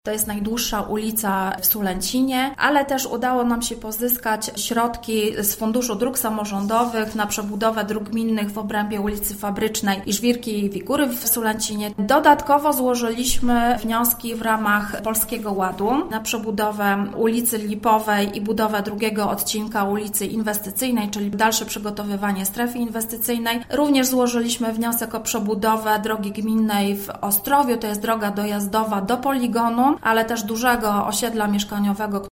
– Rozpoczęliśmy już przebudowę ulicy Jana Paska, a to jedna z największych inwestycji drogowych w gminie – mówi Iwona Walczak, zastępca burmistrza Sulęcina: